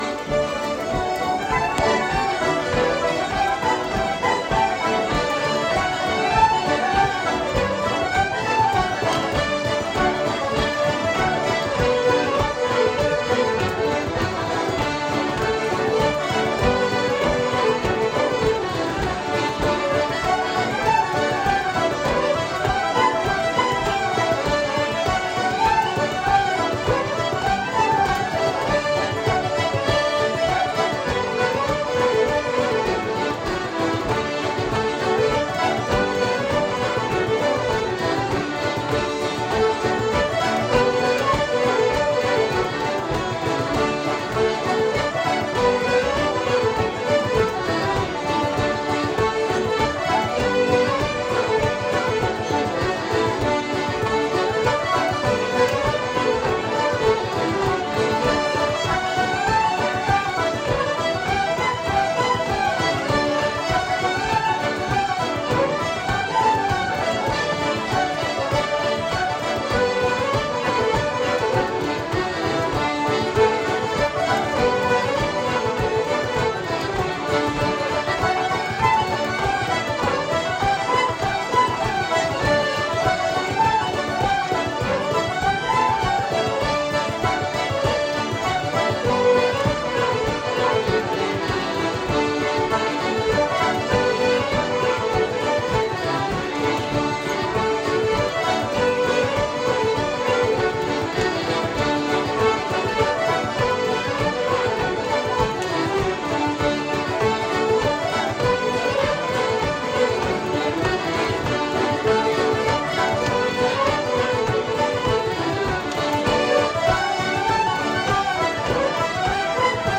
Irish Arts week Katskills, NY
Some of the music fron the Weekend